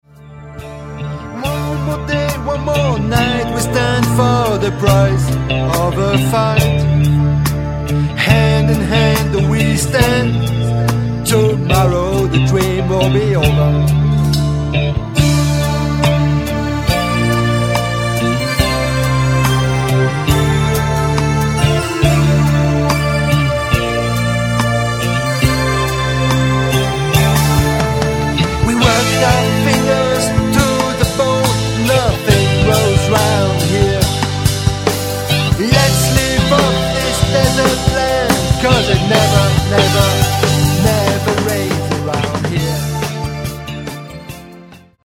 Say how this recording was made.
recorded at Colors Music Studio, Neuchâtel, Switzerland